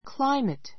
climate kláimit ク ら イメ ト 名詞 ❶ 気候 類似語 climate はある地域特有の気象状態をいう.